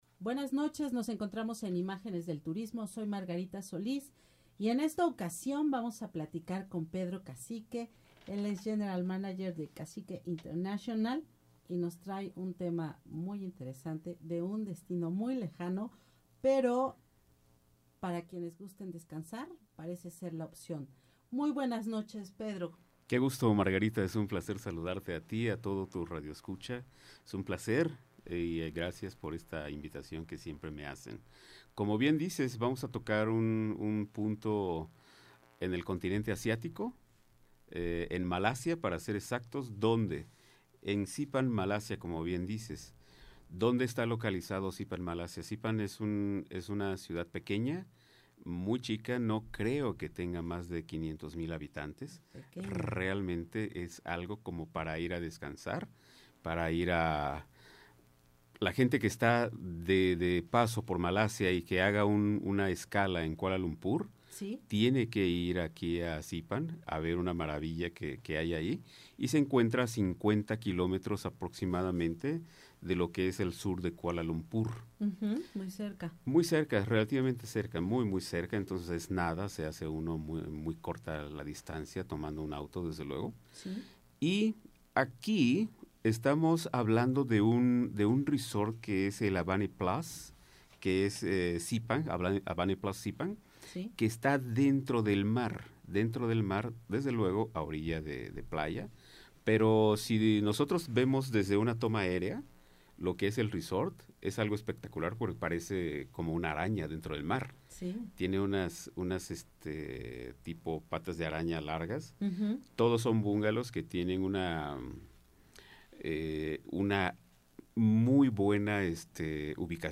Sintonízanos por Imagen Radio 90.5 FM.